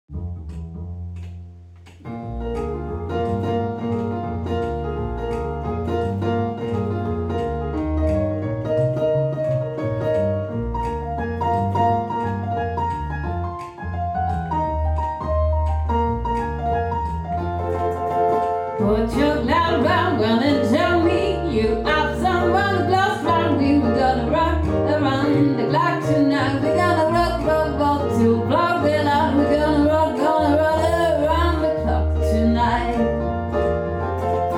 Jazz / Swing / Rock